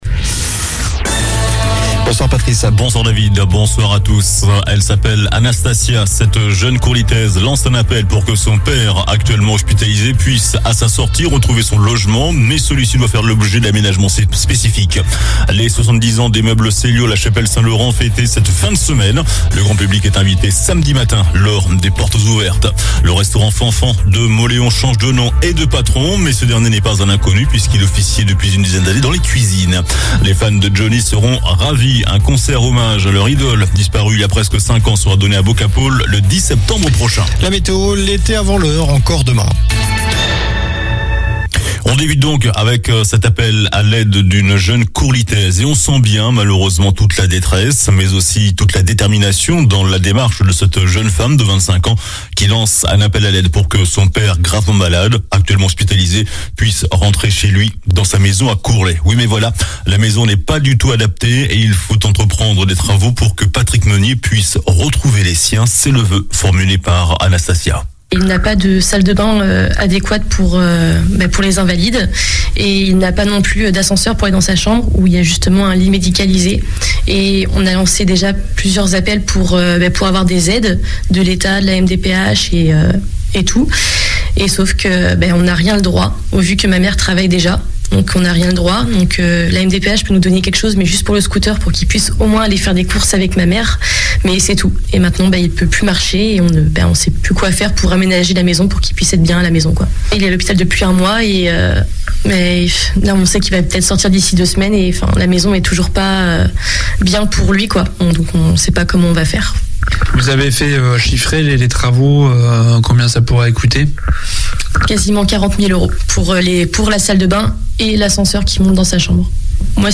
JOURNAL DU LUNDI 09 MAI ( SOIR )